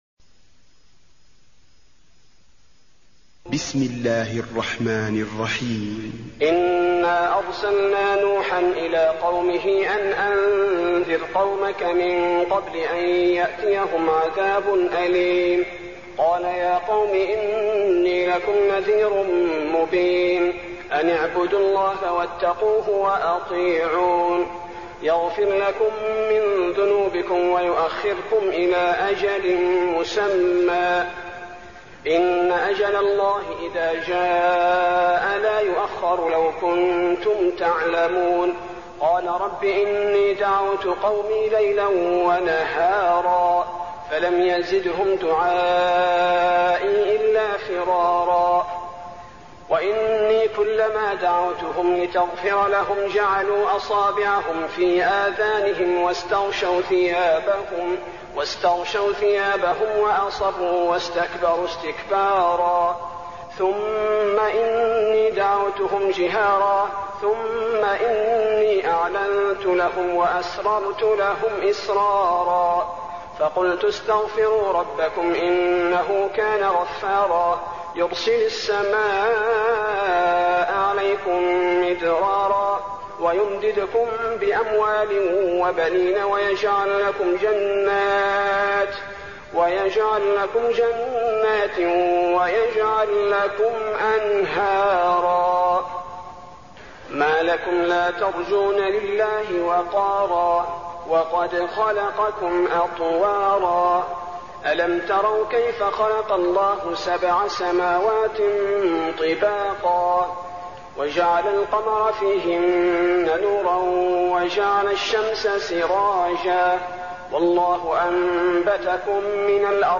المكان: المسجد النبوي نوح